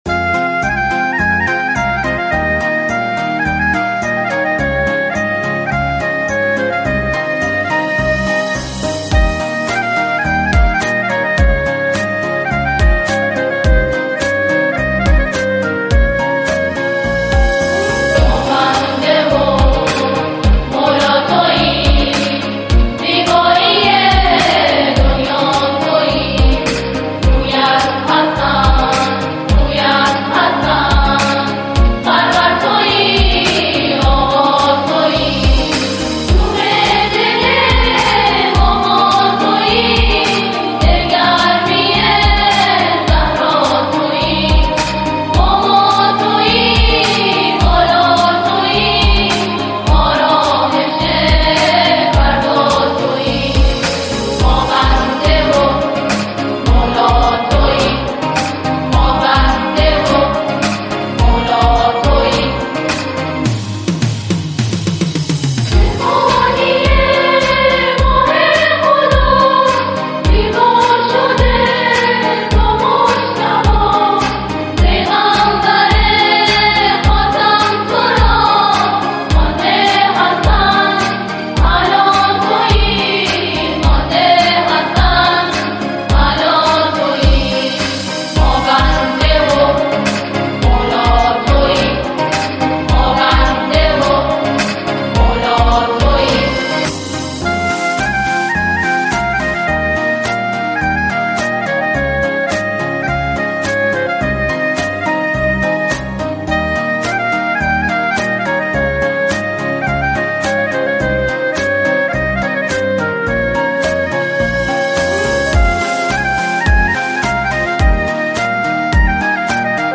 گروه سرود دخترانه
آهنگ این اثر ملودی زیبا و تأثیر گذاری دارد.